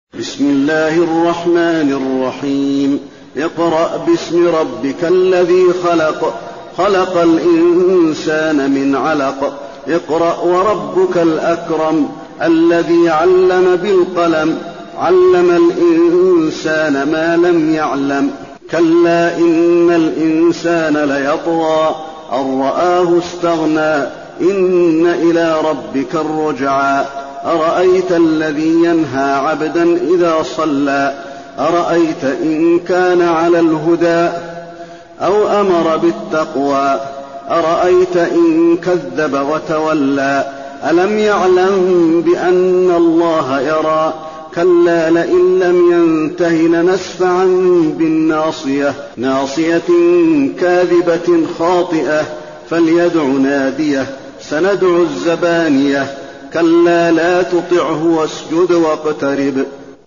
المكان: المسجد النبوي العلق